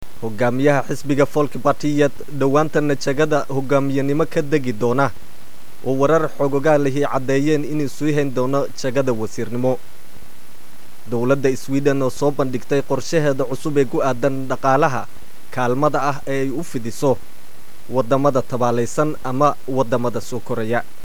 Here’s another recording of a news bulletin in a mystery language.